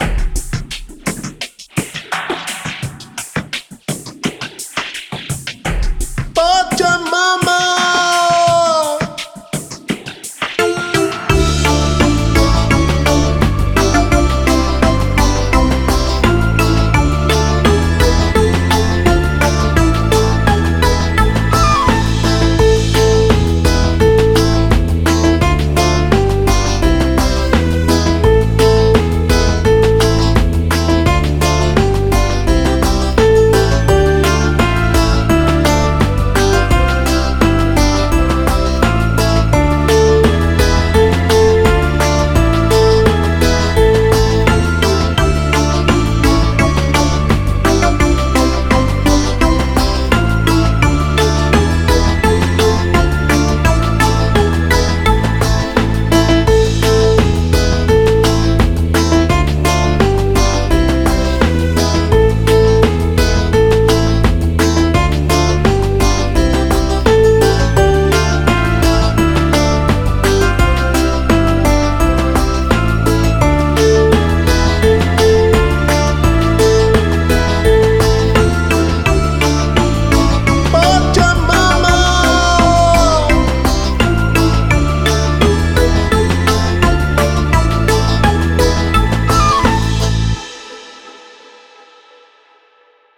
• Категория: Детские песни
Слушать минус
караоке